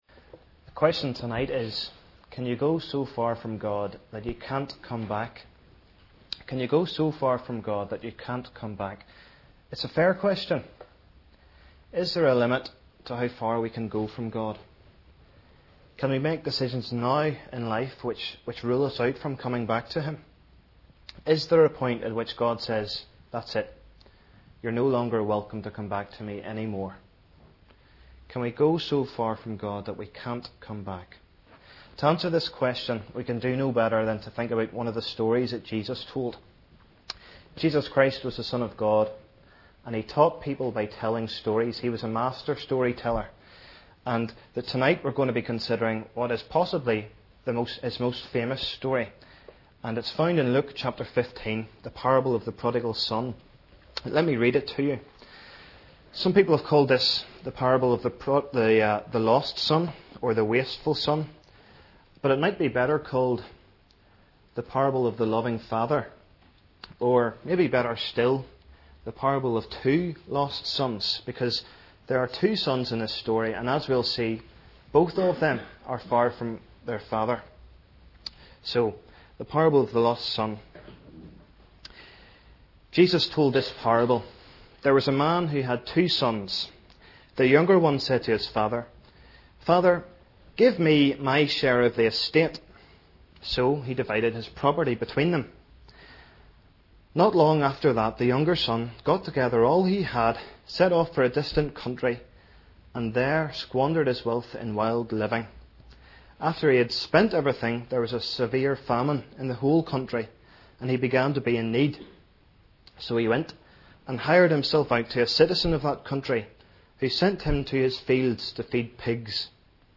Sermons at New Life Fellowship